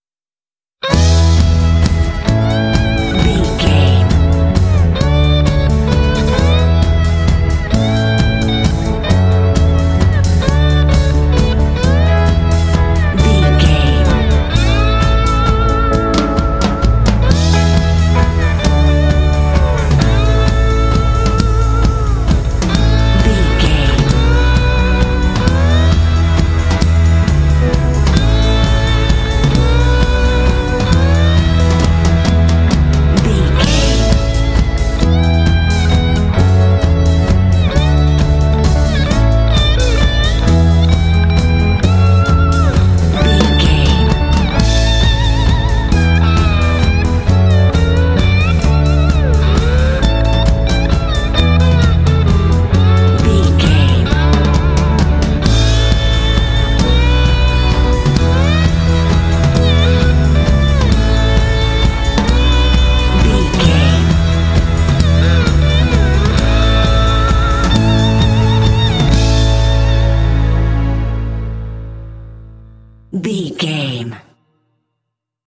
This soft rock music is ideal for main menu pages.
Ionian/Major
melancholy
fun
drums
electric guitar
bass guitar
soft rock